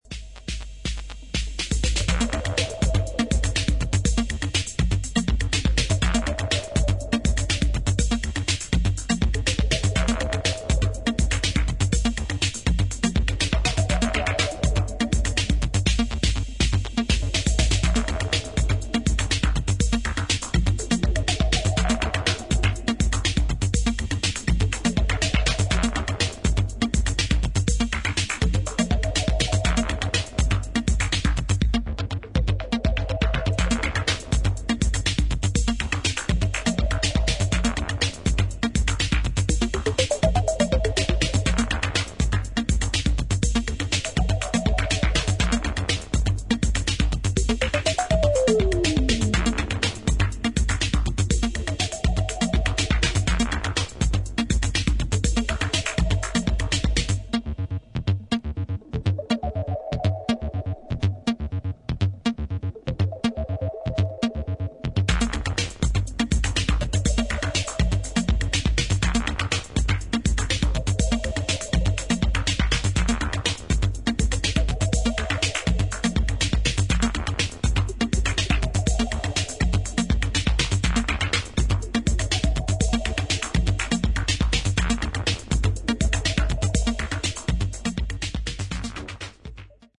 今聴くとレトロ・フューチャーな感触を覚える作品です。